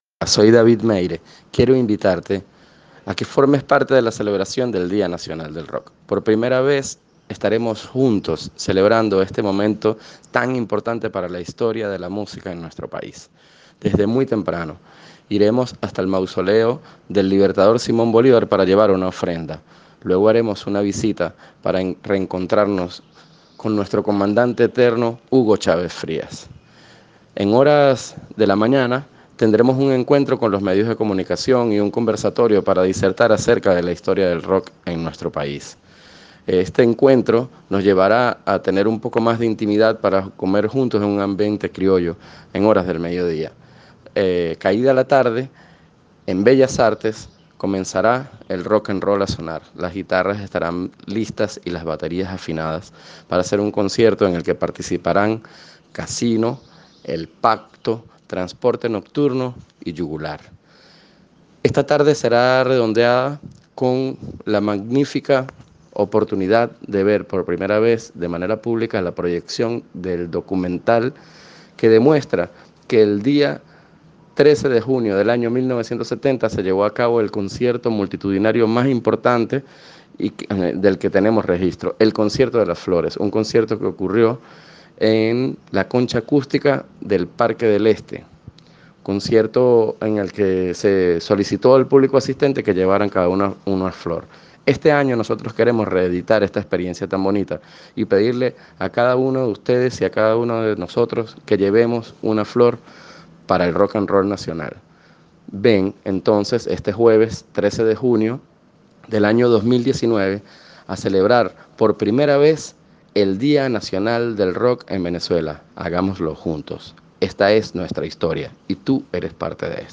El Presidente del Iaem, David Meire invita a la celebración del Día Nacional del Rock
Nota-de-Voz-David-Meire-Ciudad-Rock-2019.ogg